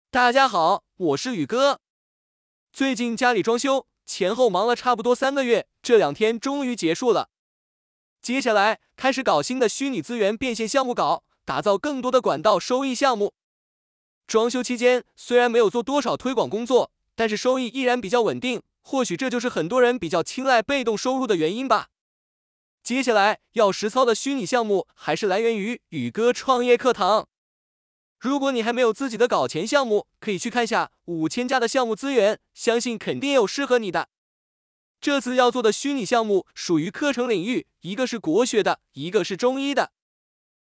一款电脑版配音软件，支持20多种音色+30多种风格。
每个音色还可以选择风格，比如严肃、生气、可爱等等，非常实用。